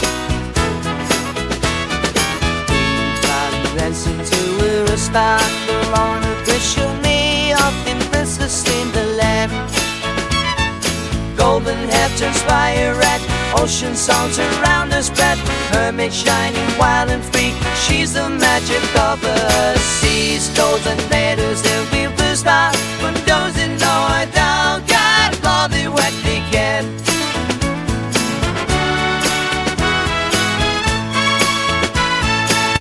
Well let’s try creating a short AI song.